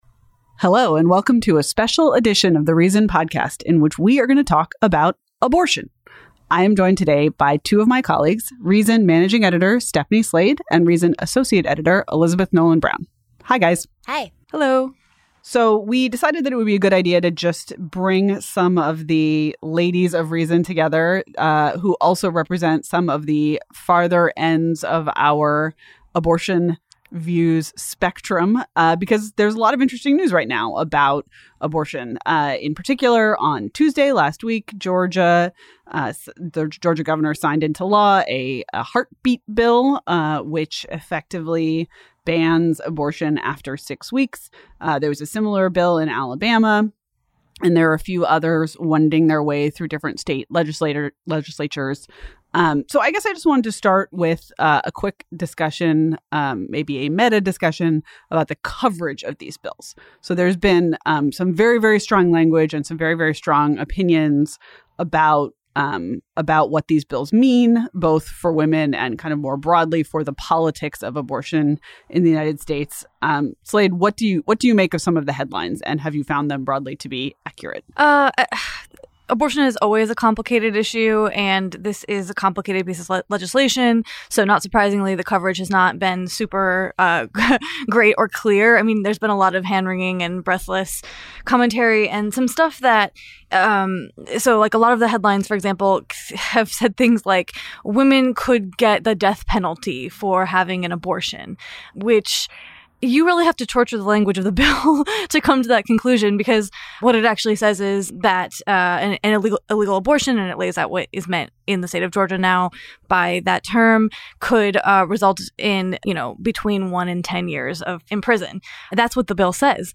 A conversation between Reason editors about Georgia's "heartbeat law," the future of Roe v. Wade, and how to be less shouty even when you disagree.